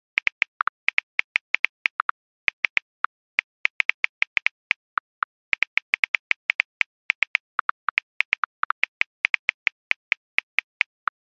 Звуки смартфона: печать на клавиатуре (набираем текст)